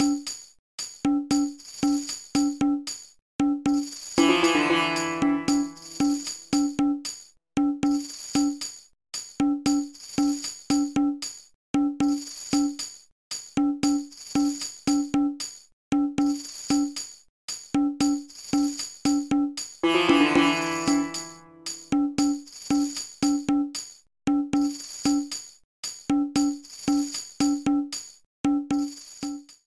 Level preview music